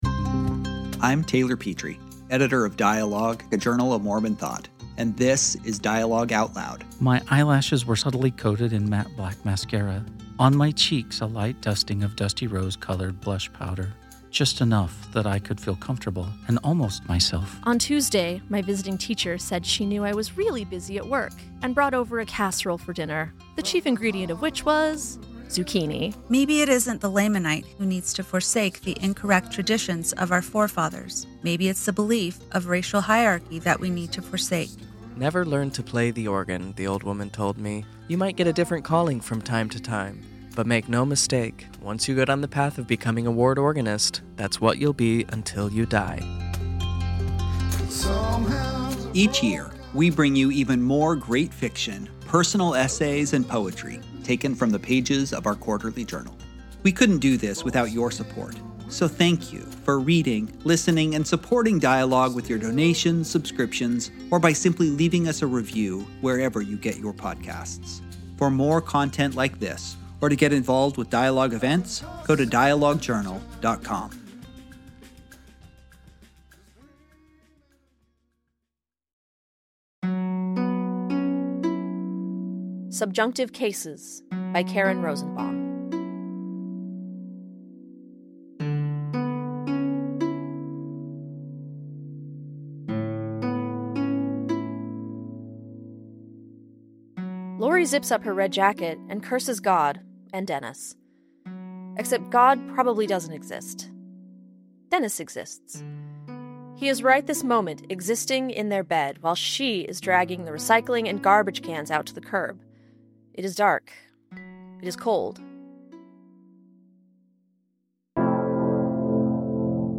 Subjunctive Cases by Karen Rosenbaum (Audio Story)